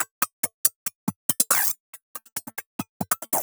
tx_perc_140_zippers.wav